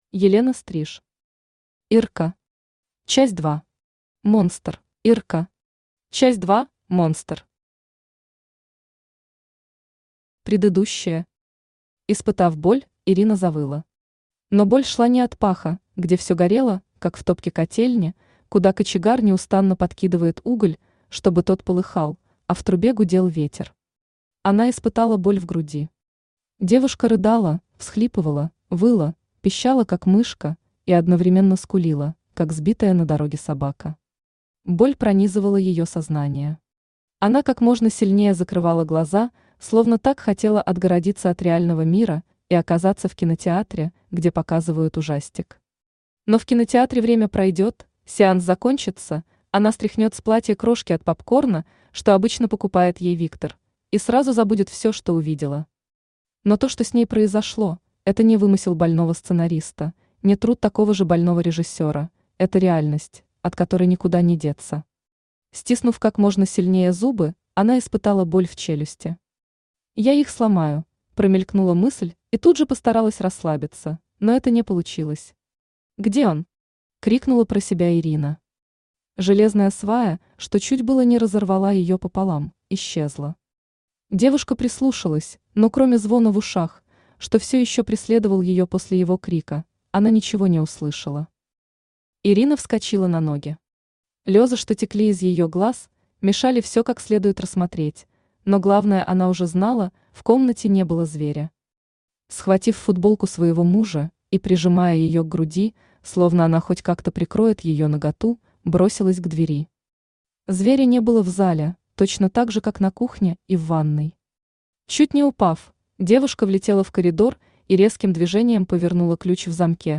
Аудиокнига Ирка. Часть 2. Монстр | Библиотека аудиокниг
Монстр Автор Елена Стриж Читает аудиокнигу Авточтец ЛитРес.